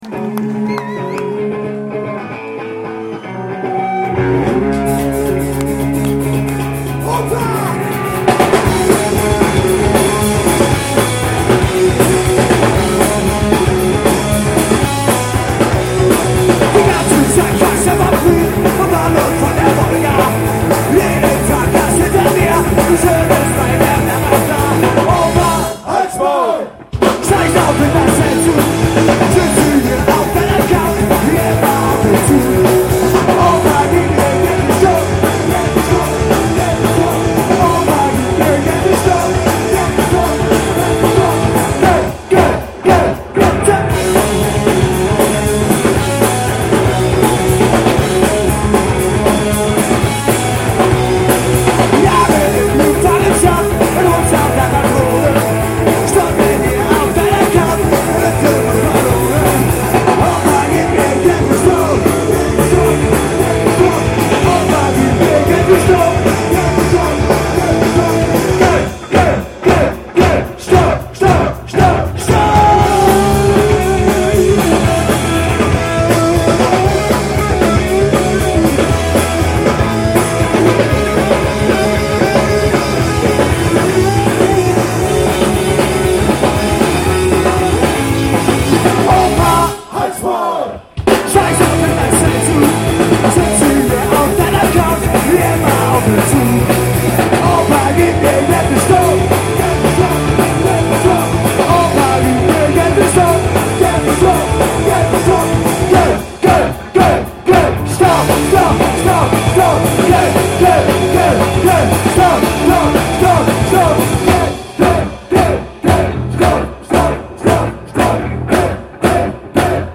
Aufnahmegerät: Sharp IM-DR420H (Mono-Modus)
Mikrofon: Sony ECM-T6 (Mono)